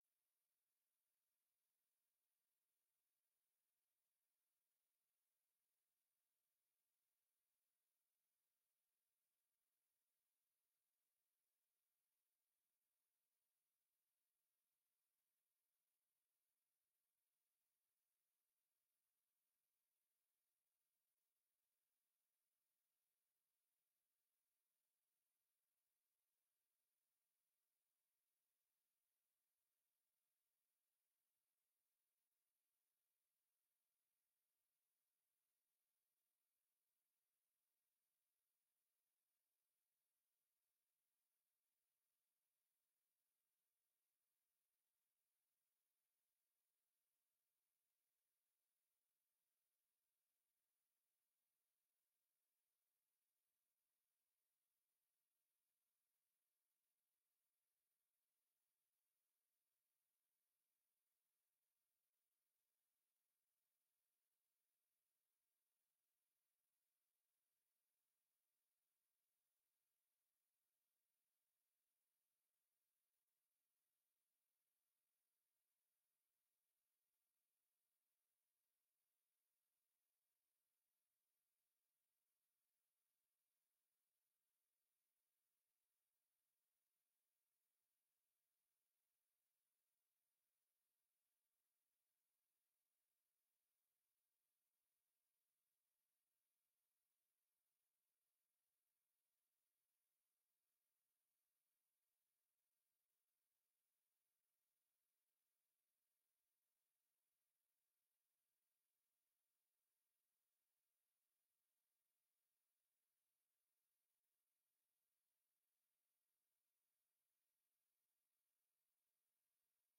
De vergadering vindt plaats in kamer 83.